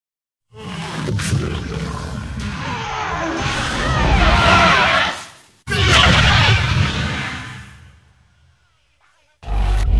Рык зомби: